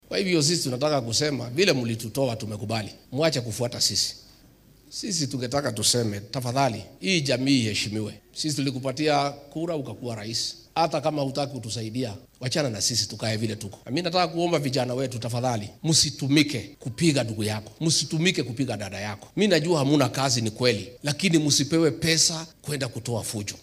Madaxweyne ku xigeenkii hore ee dalka Rigathi Gachagua ayaa da’yarta kula taliyay in ay taxadaraan oo aan loo adeegsan rabshado lagu fulinaya dano siyaasadeed. Xilli uu ku sugnaa deegaanka Mukurweini ee ismaamulka Nyeri ayuu dhanka kale Gachagua sheegay in gobolka Bartamaha dalka uu laga billaabo sanadka 2025-ka dib u soo ceshan doono sarreyntiisi siyaasadeed iyadoo la soo dhisaya xisbi cusub.